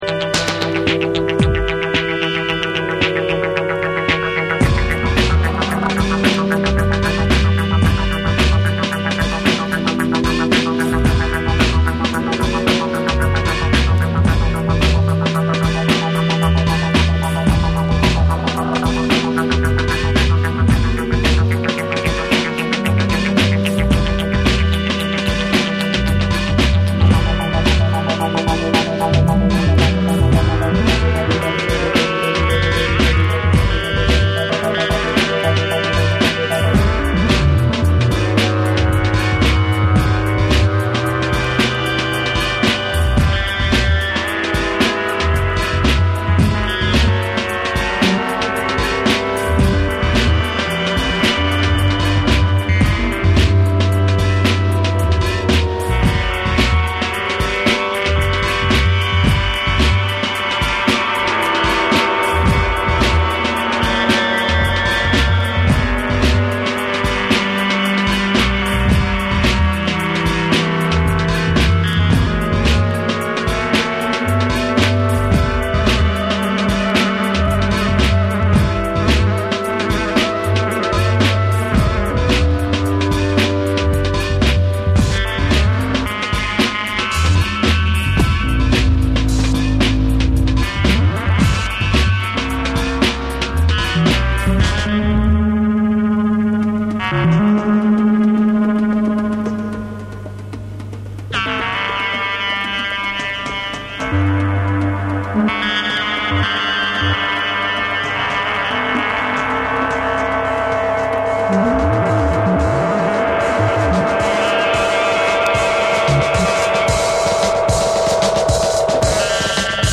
混沌とした空気感とエレクトロニクスが融合した実験性の高いナンバーを収録。
BREAKBEATS